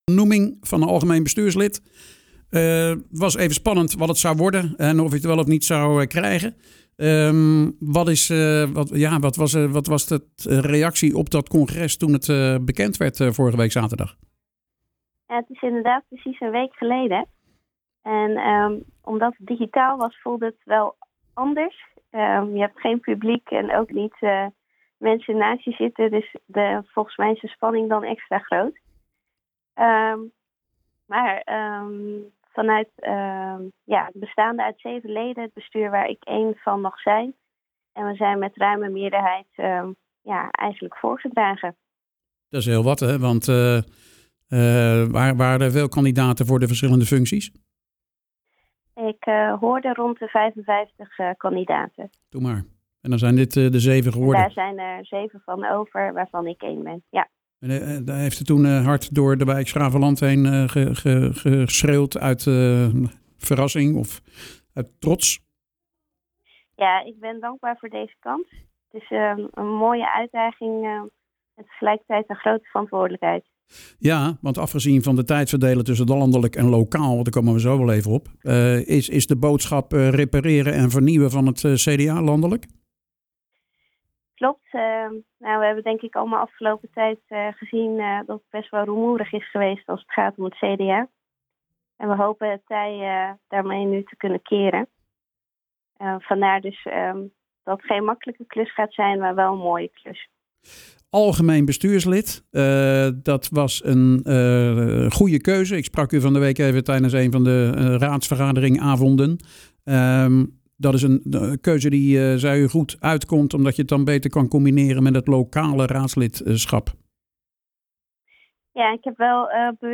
praat met Dewie Badloe over het combineren van de functie van algemeen bestuurslid CDA landelijk en kandidaat voor een nieuwe termijn als raadslid voor het CDA.